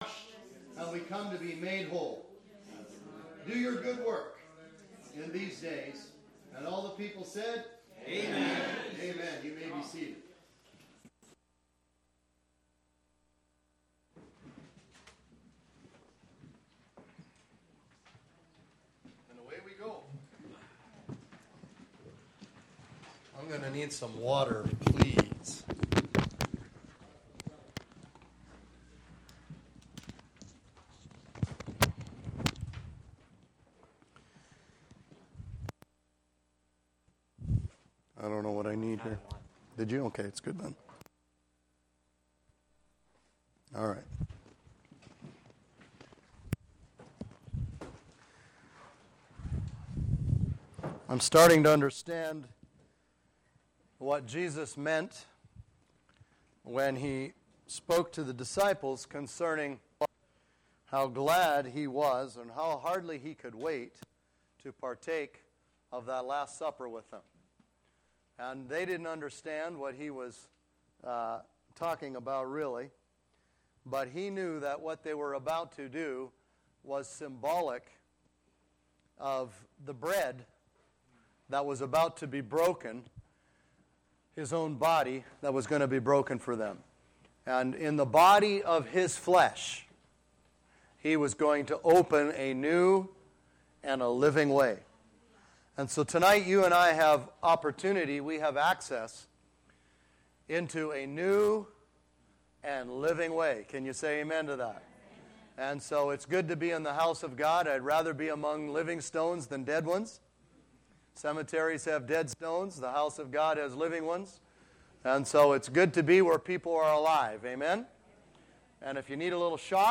Posted in Teachings